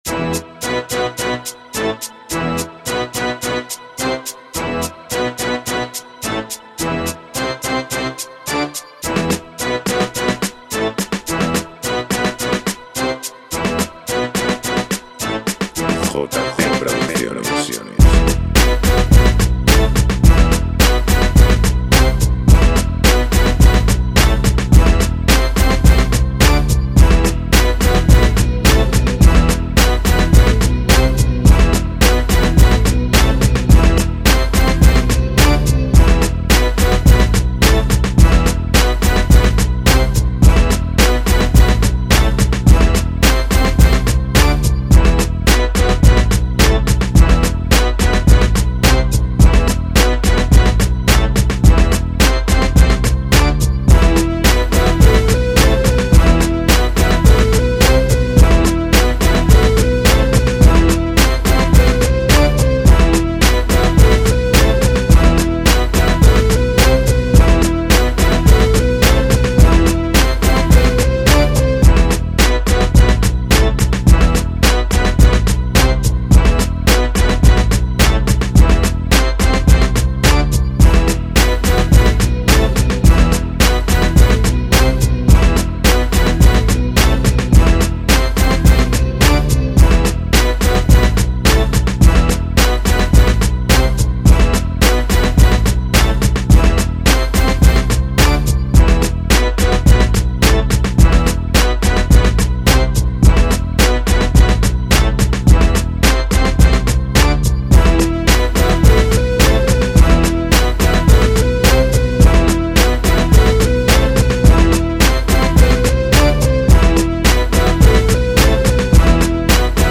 NUEVA BASE